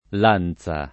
lanza [ l # n Z a ] → lancia